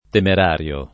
temerario [ temer # r L o ]